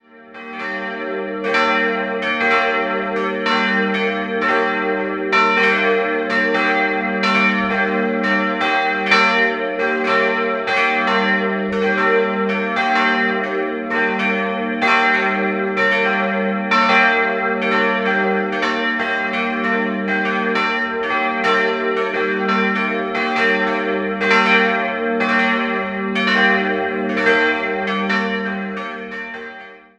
3-stimmiges Geläut: g'-b'-d''